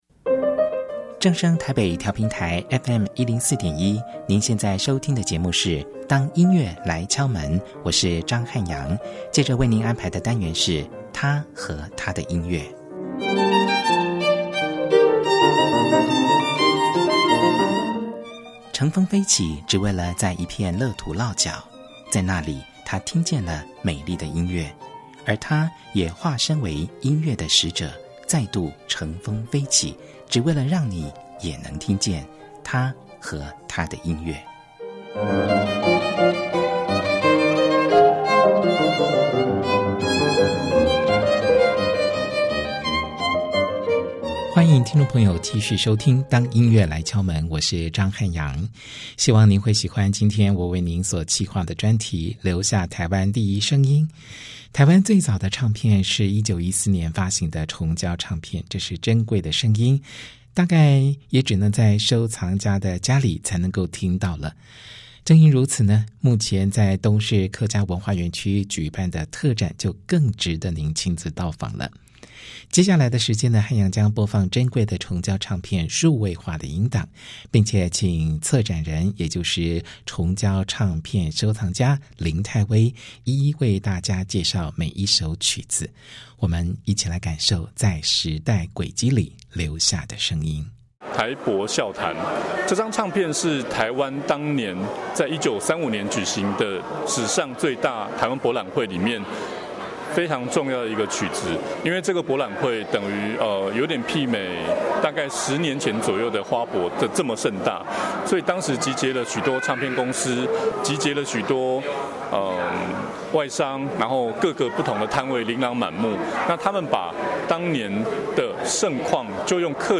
台中市政府客家事務委員會在東勢客家文化園區舉辦《留下台灣第一聲音∼曲盤裡肚?客家愛情音樂同日常》特展，7月13日開幕當天，我到現場採訪，仔細參觀之餘，決定以專題報導的方式錄製成一集節目，將這項活動推薦給大家。